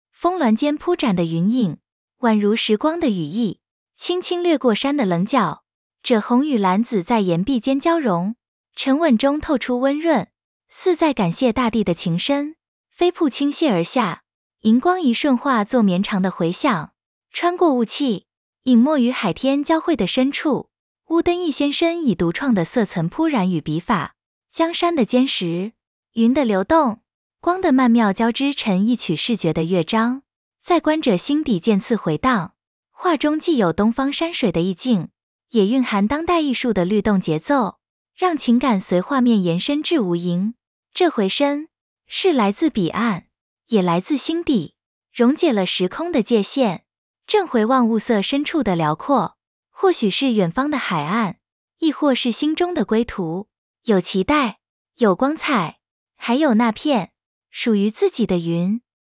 中文語音導覽